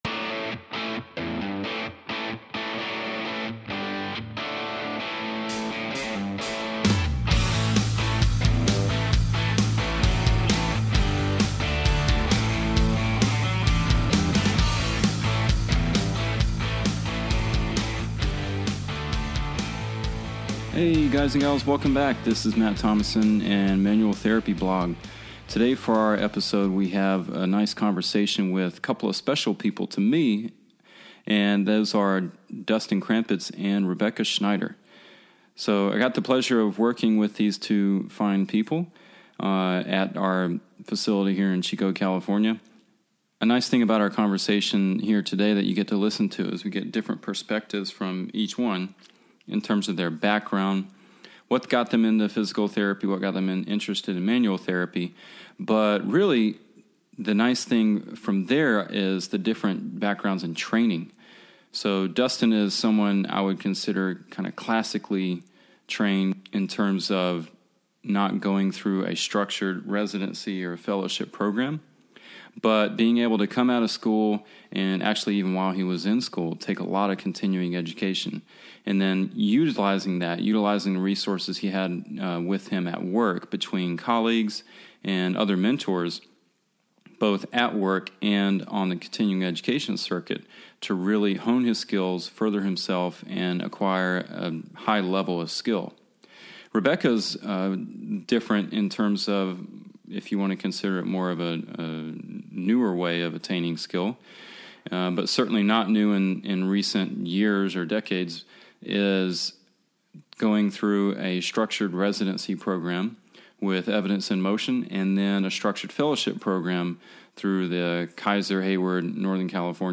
Our most recent conversation is with two of my friends and colleagues here in Chico, CA.